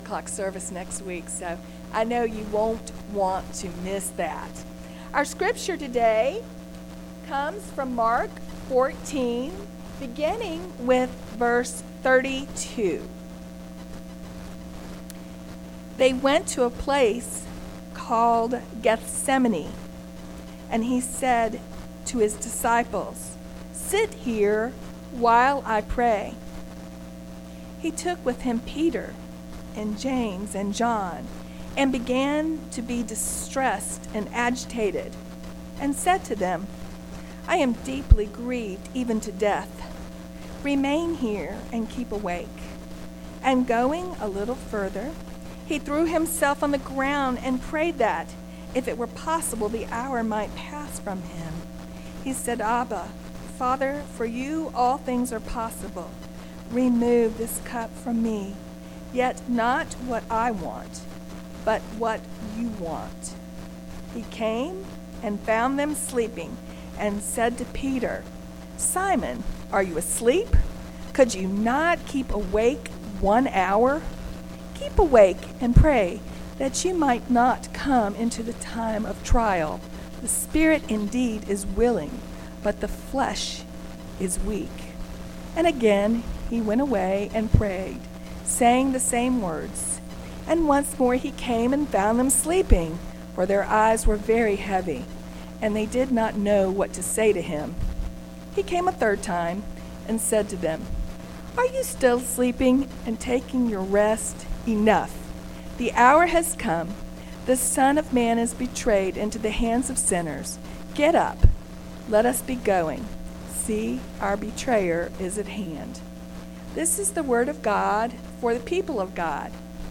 Chatsworth First Methodist Church Sermons